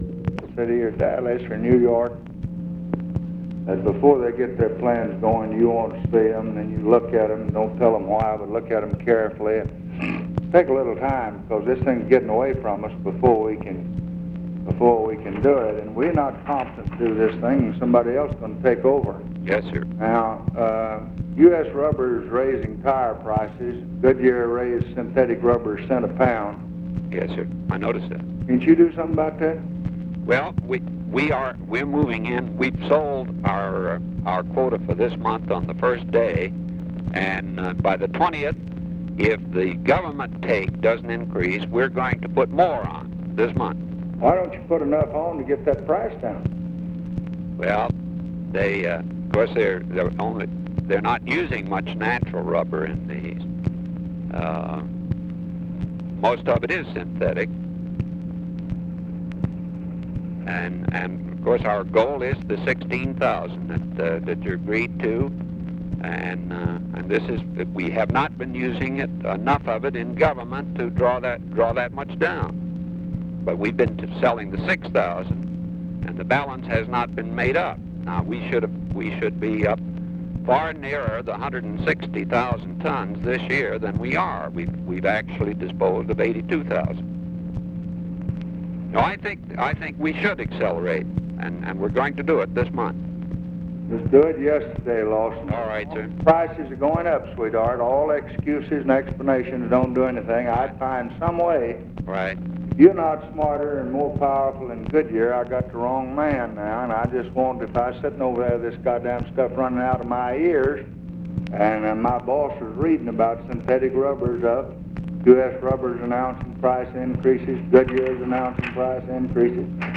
Conversation with LAWSON KNOTT, March 7, 1966
Secret White House Tapes